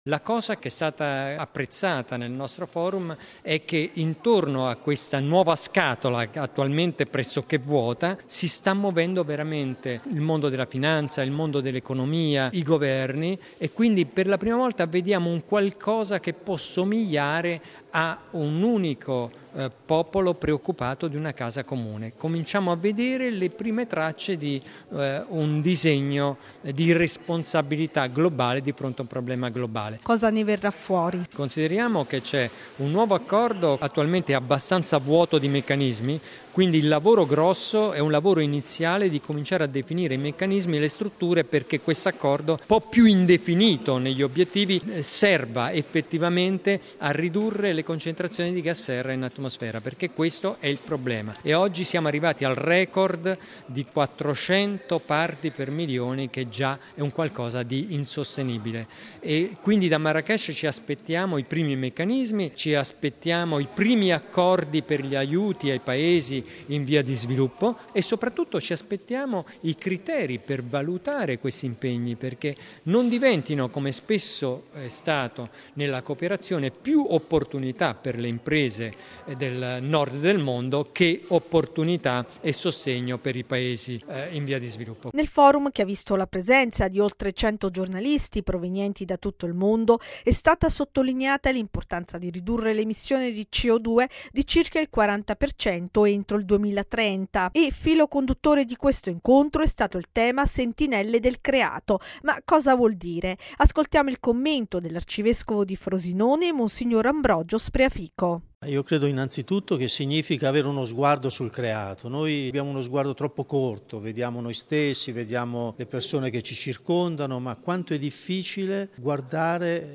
Ascoltiamo il commento dell’arcivescovo di Frosinone mons. Ambrogio Spreafico: